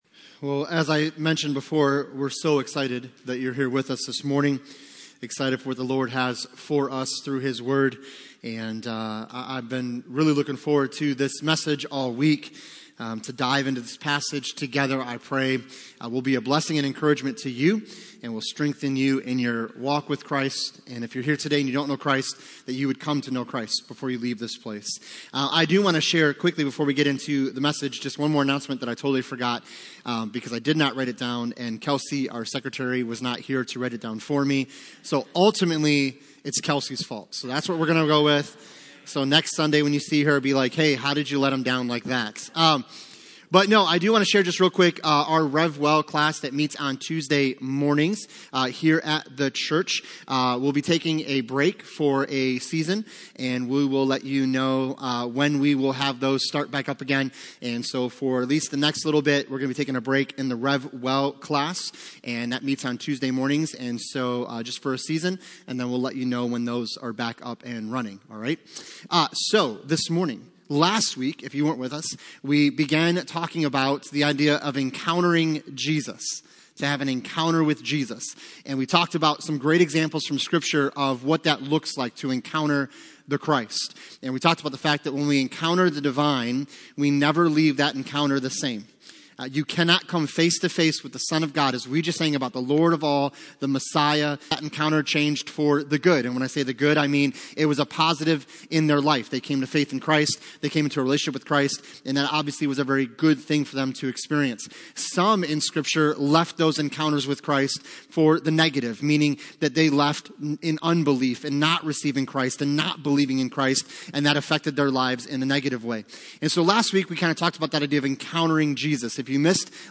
Passage: Isaiah 53:1-3 Service Type: Sunday Morning